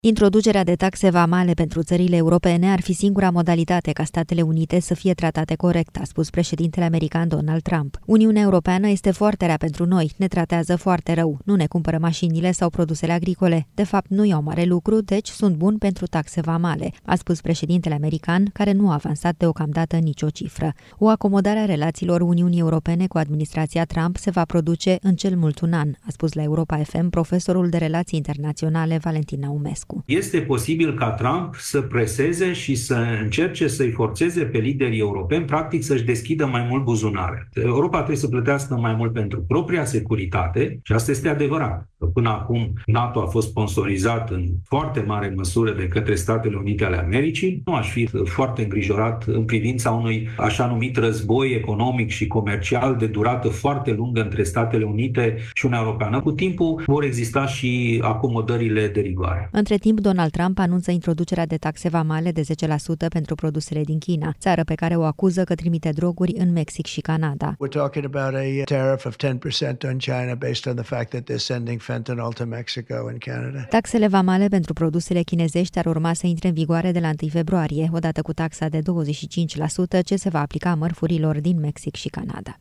Introducerea de taxe vamale pentru țările europene ar fi singura modalitate ca Statele Unite să fie tratate corect, a spus președintele american Donald Trump: